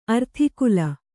♪ arthikula